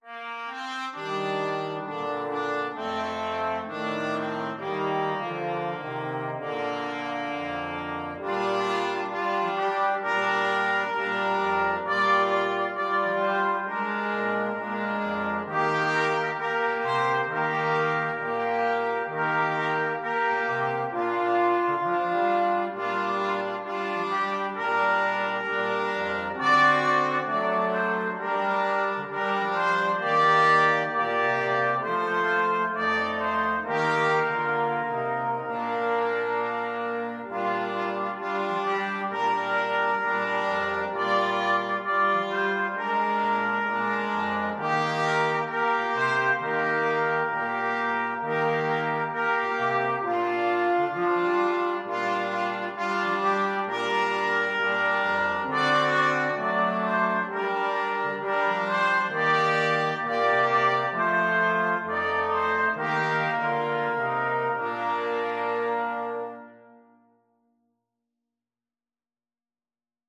4/4 (View more 4/4 Music)
Brass Quartet  (View more Easy Brass Quartet Music)
Traditional (View more Traditional Brass Quartet Music)
Rock and pop (View more Rock and pop Brass Quartet Music)